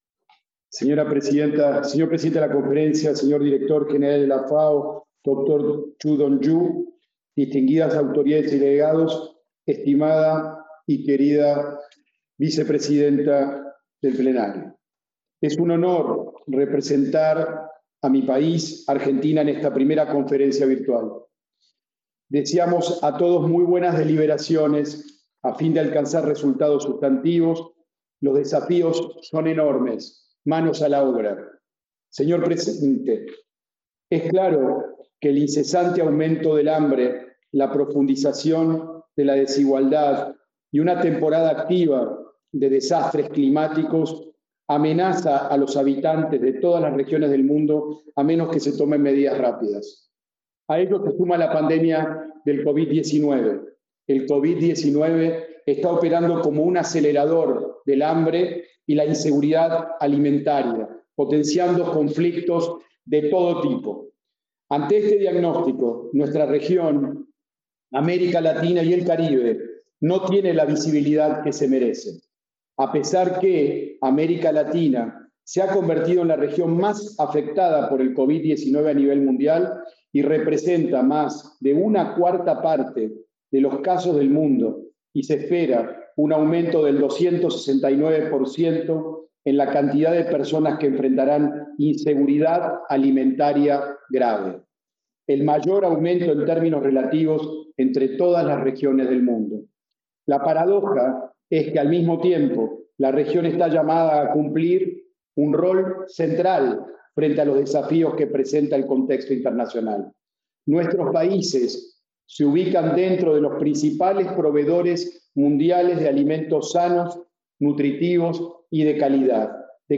GENERAL DEBATE
Addresses and Statements
Excmo. Sr. Don Carlos Bernardo Cherniak Embajador Representante Permanente de la República Argentina ante la FAO
(Plenary – Español)